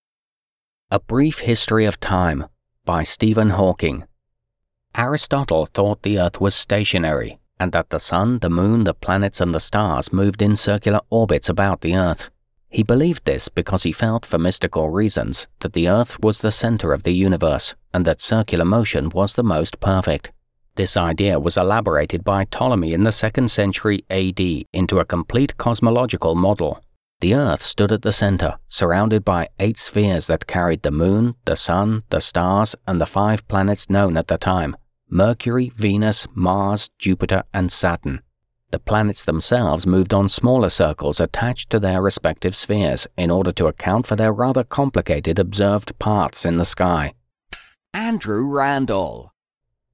Male
Adult (30-50), Older Sound (50+)
British UK English male - Amusing, Attractive, Authoritative, Believable, Calming, Classy, Comedy, Commercial, Conversational, Corporate, Educational, Friendly, Informative, Natural
Narration
Words that describe my voice are Attractive, Believable, Conversational.